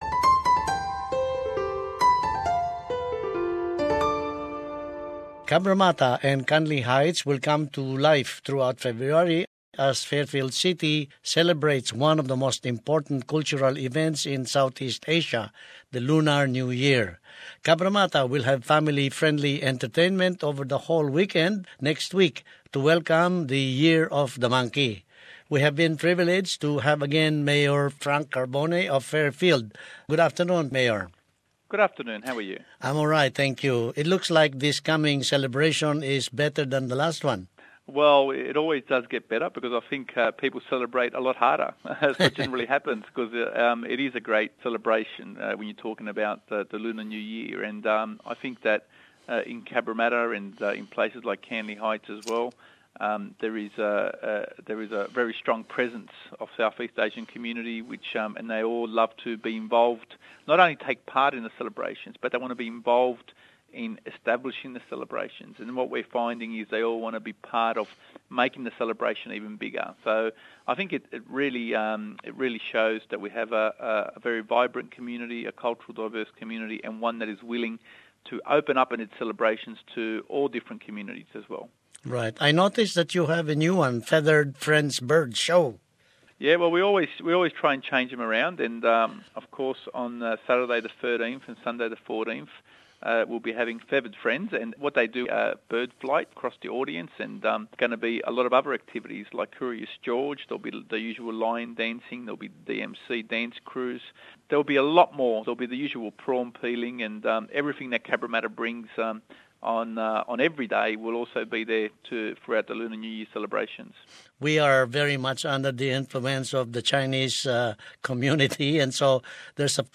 The mayor of Fairfield City, Frank Carbone, shares with us the information about the cultural activities in the coming two weeks.